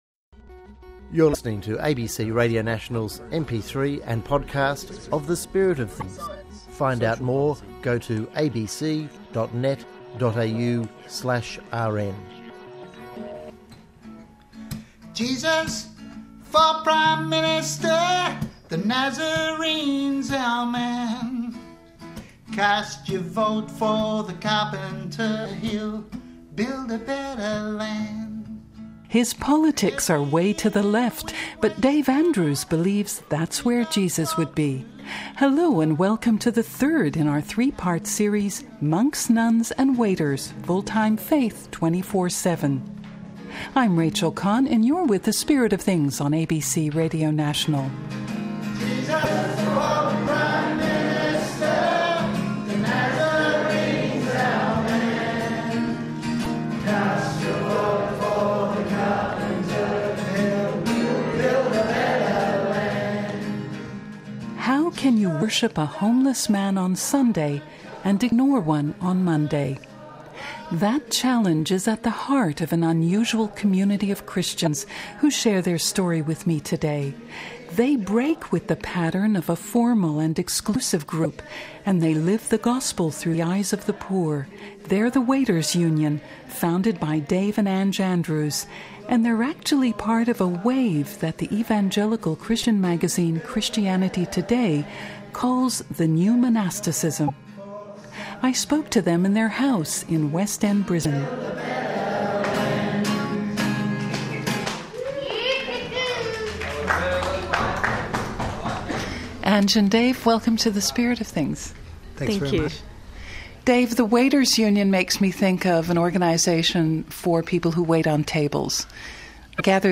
The Waiters Union - Spirit of Things Interview
The Spirit of Things is a radio programme on ABC Radio National.